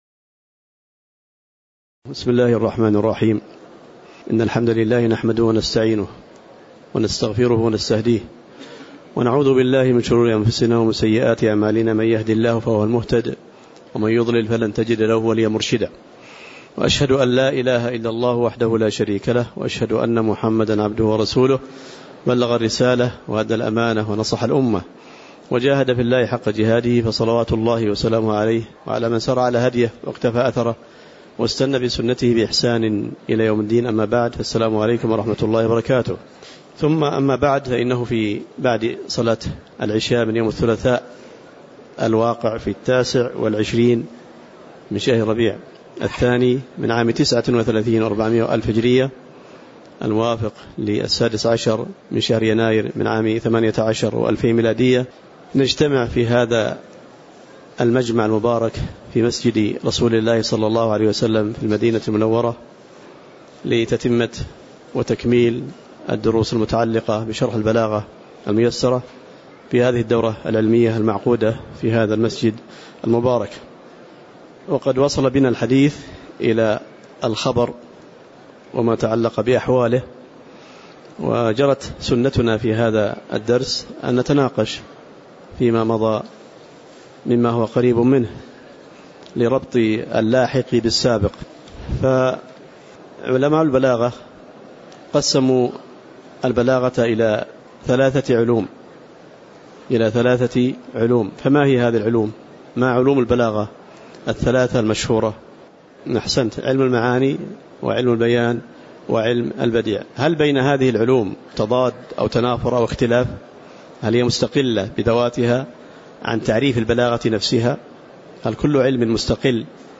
تاريخ النشر ٢٩ ربيع الثاني ١٤٣٩ هـ المكان: المسجد النبوي الشيخ